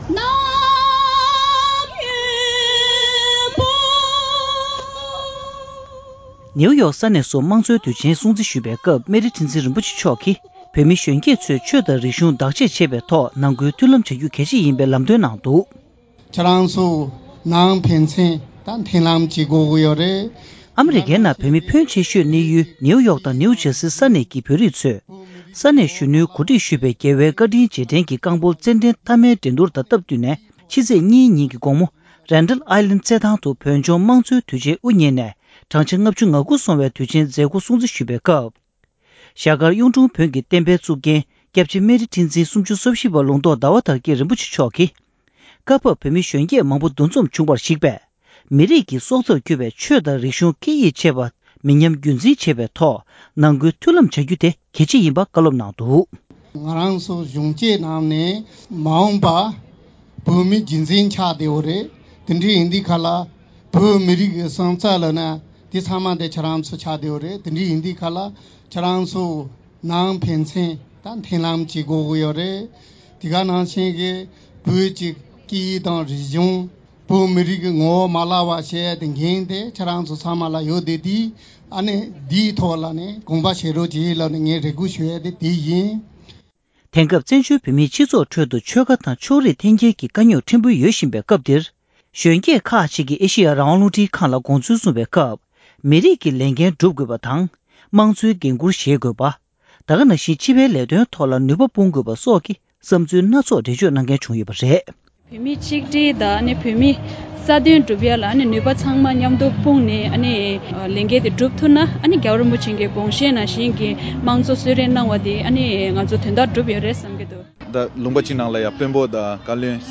ཨ་རིའི་གྲོང་ཁྱེར་ནིའུ་ཡོག་ཏུ་མང་གཙོ་དུས་དྲན་ཐོག་བཀའ་སློབ།
སྒྲ་ལྡན་གསར་འགྱུར།